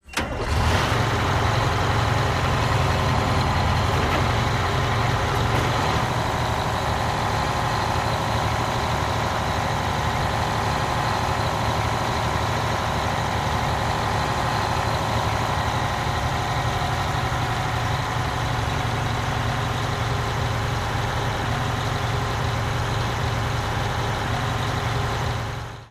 tr_dieseltruck_idle_01_hpx
Diesel truck starts and idles. Vehicles, Truck Idle, Truck Engine, Motor